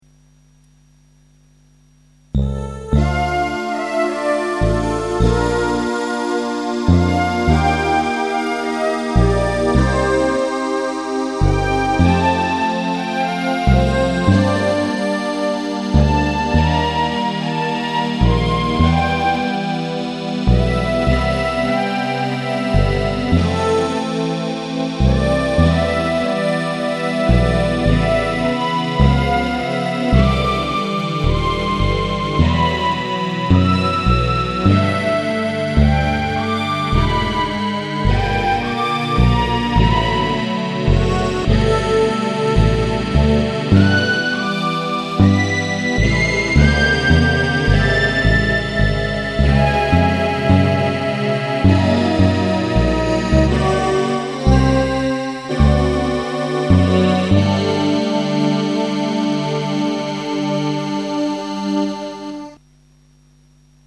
LucyTuned song